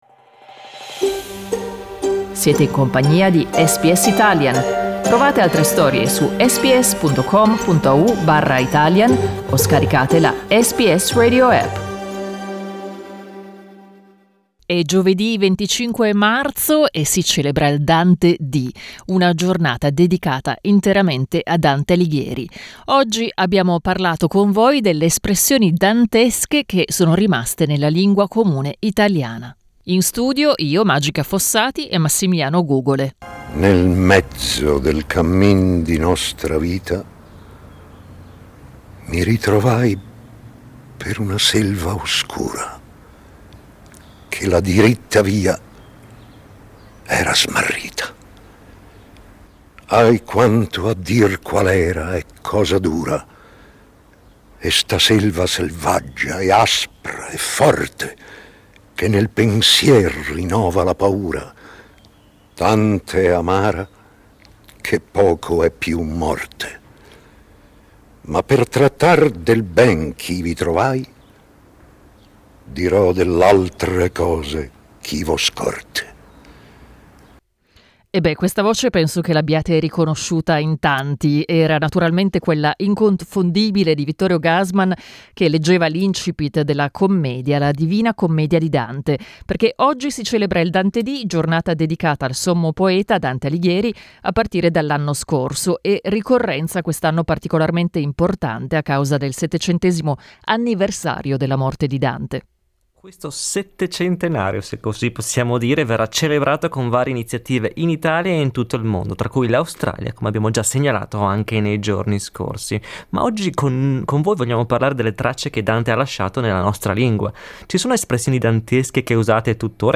In collegamento da Firenze